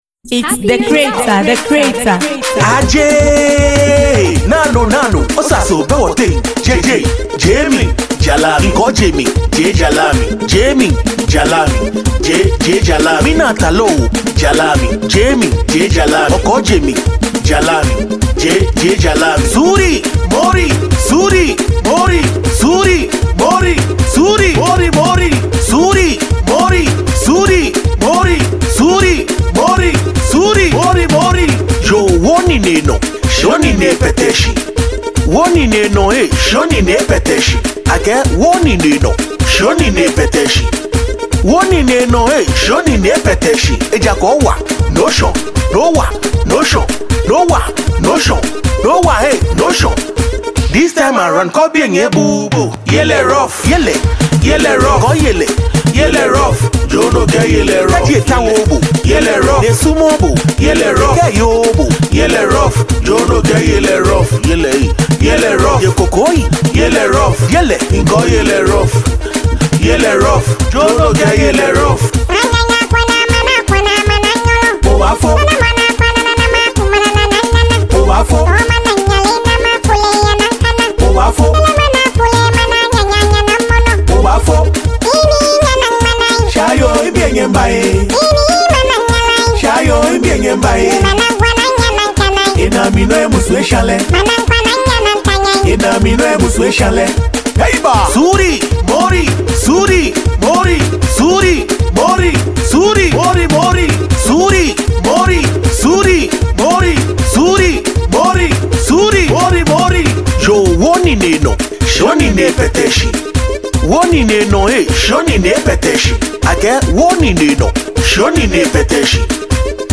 a danceable production for fans and music lovers